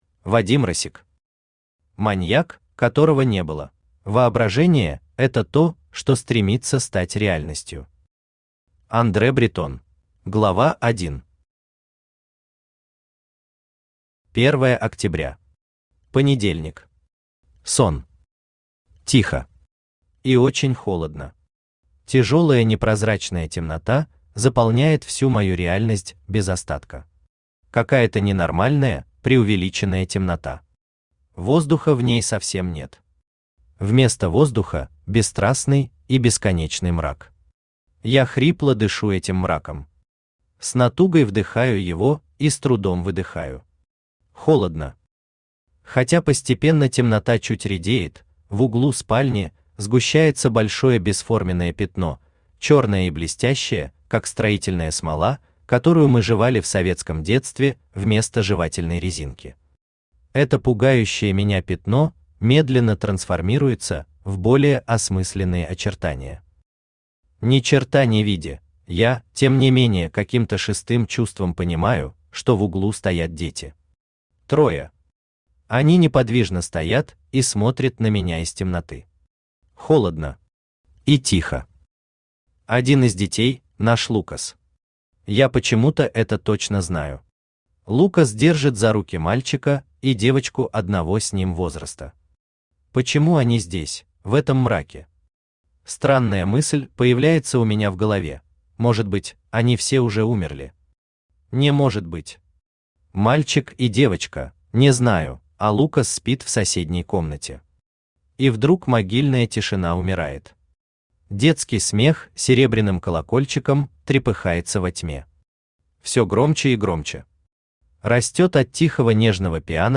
Аудиокнига Маньяк, которого не было | Библиотека аудиокниг
Aудиокнига Маньяк, которого не было Автор Вадим Россик Читает аудиокнигу Авточтец ЛитРес.